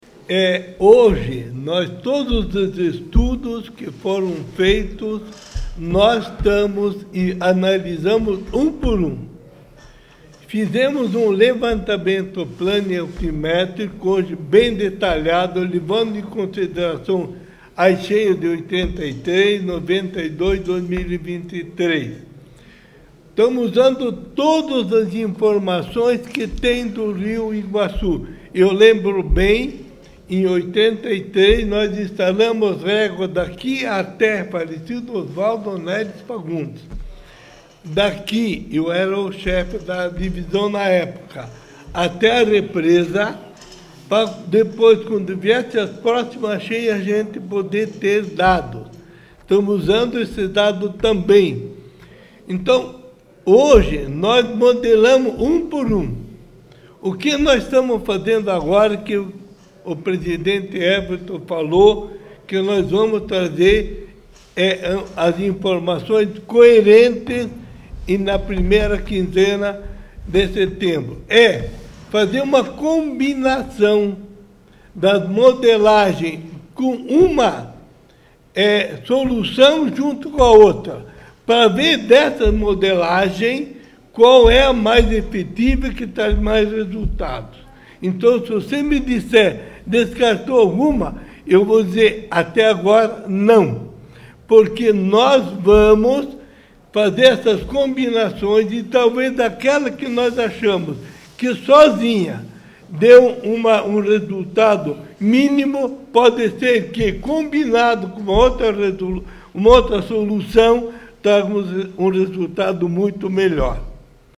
Durante a coletiva, foi destacado que a geografia de União da Vitória — localizada em uma área de confluência e cercada por morros — a torna naturalmente mais suscetível a alagamentos severos.